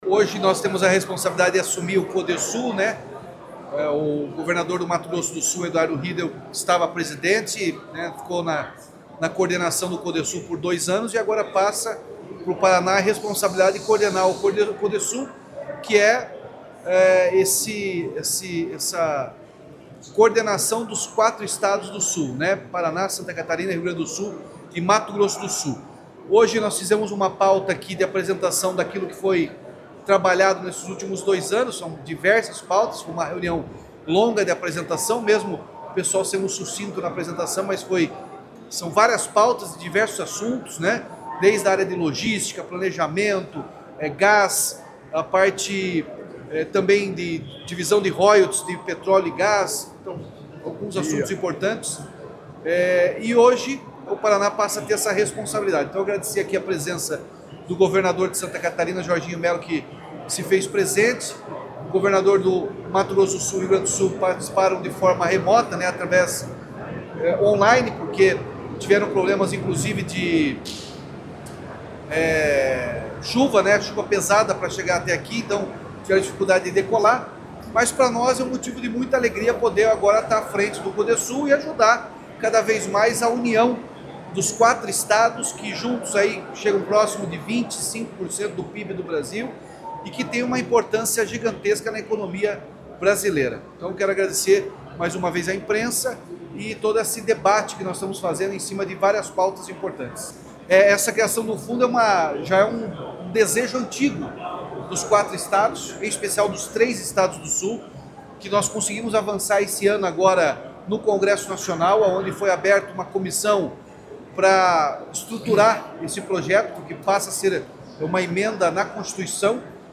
Sonora do governador Ratinho Junior sobre o Codesul